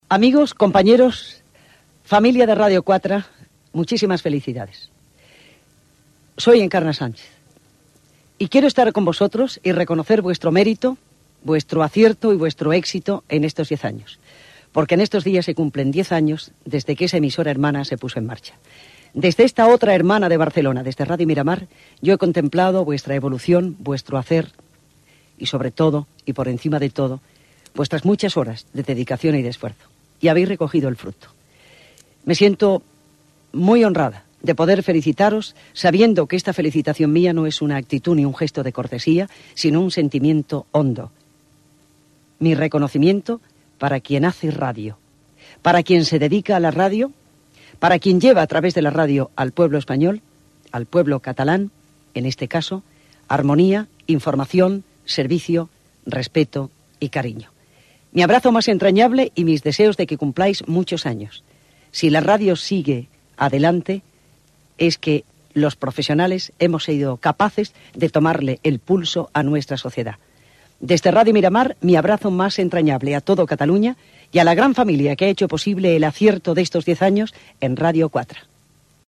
Felicitació pel 10è aniversari de Ràdio 4 d'Encarna Sánchez des de Radio Miramar